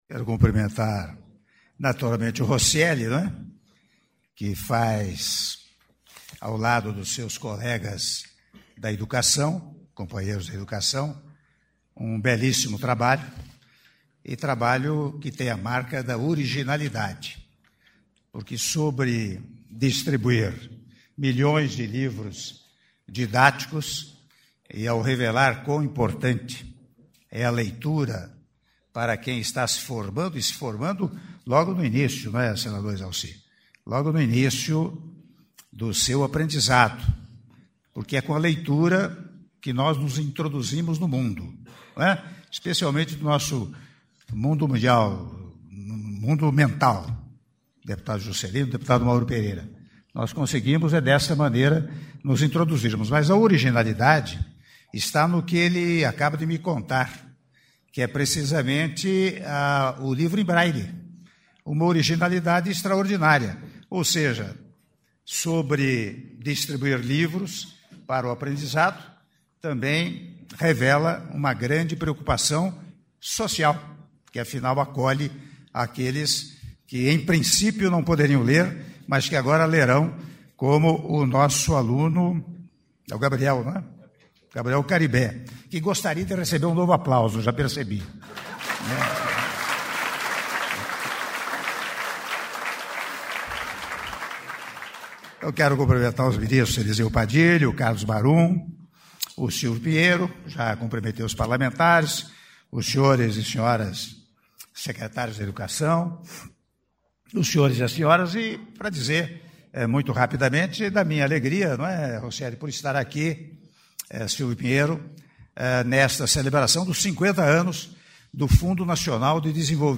Áudio do discurso do Presidente da República, Michel Temer, durante solenidade em comemoração aos 50 anos do Fundo Nacional de Desenvolvimento da Educação - FNDE - Palácio do Planalto (04min58s) — Biblioteca